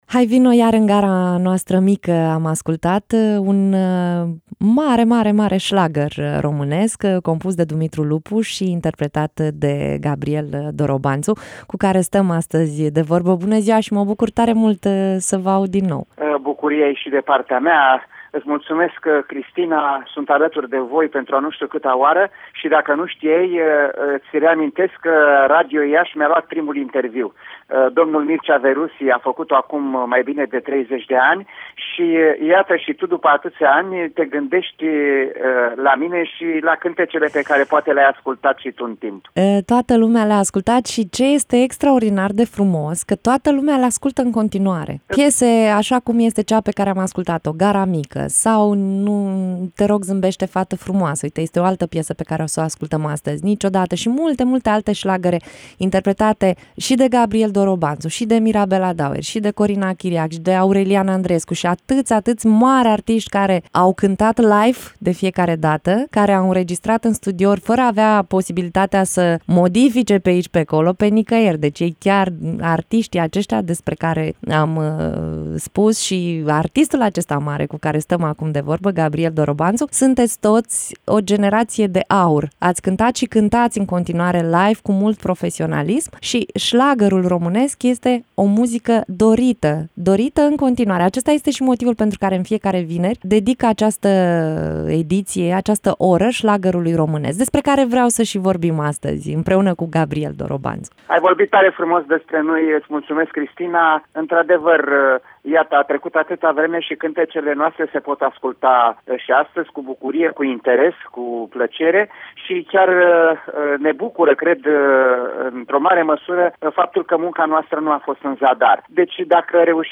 GABRIEL DOROBANŢU invitat în direct la PROMUSICA